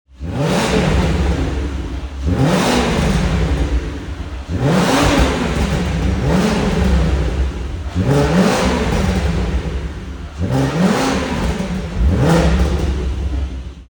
Listen to the VR6 symphony!
• Chrome Exhaust Tailpipes
• 3.2L VR6 Engine: 240PS/320nm